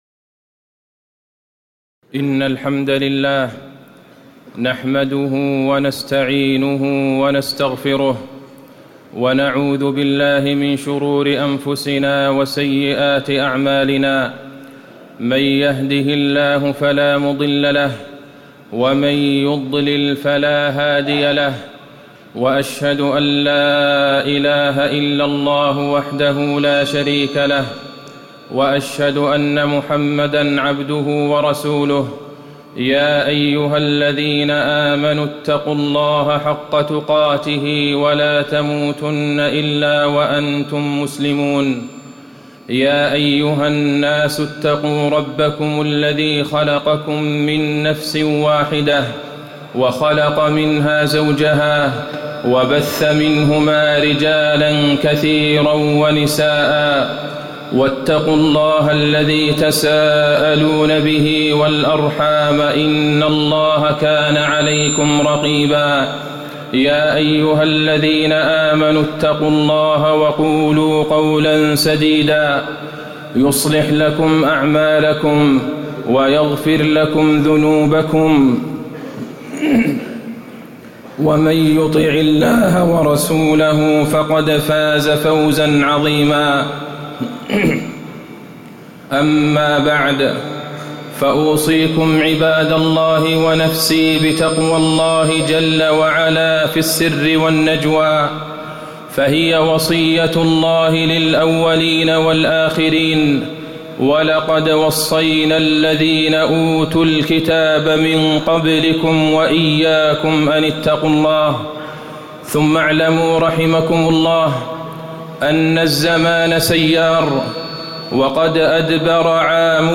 تاريخ النشر ٦ محرم ١٤٣٨ هـ المكان: المسجد النبوي الشيخ: فضيلة الشيخ د. عبدالله بن عبدالرحمن البعيجان فضيلة الشيخ د. عبدالله بن عبدالرحمن البعيجان العام الجديد وشهر الله المحرم